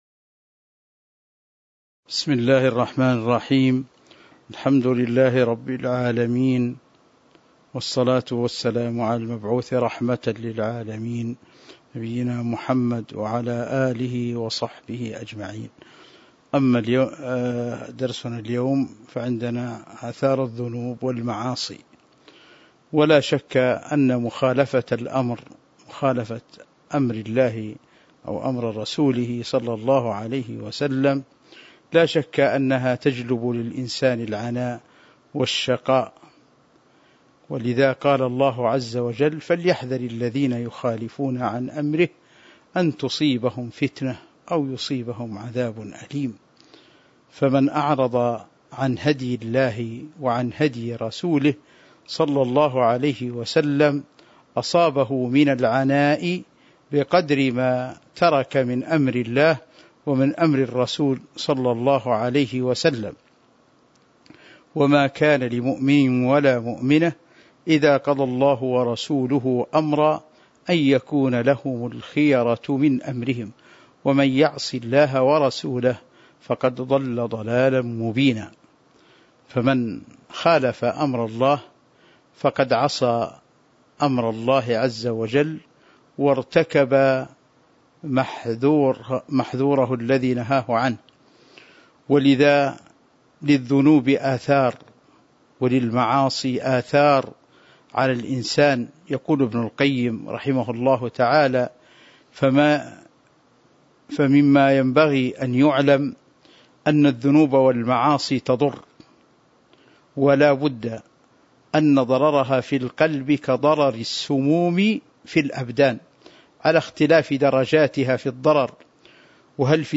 تاريخ النشر ٦ صفر ١٤٤٢ هـ المكان: المسجد النبوي الشيخ